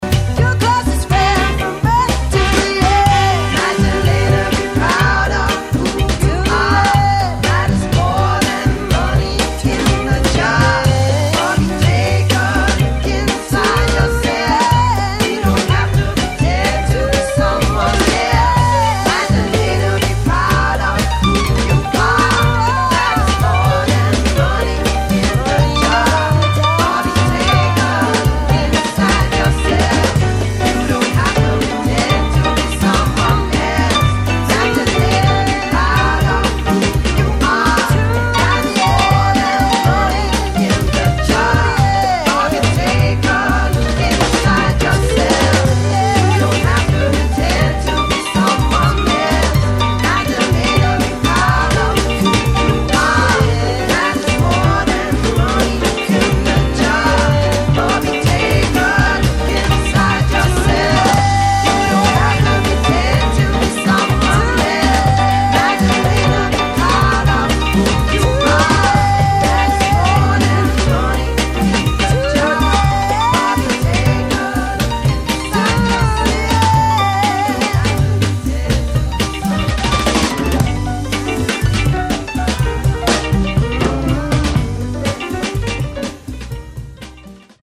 [ SOUL / FUNK / LATIN ]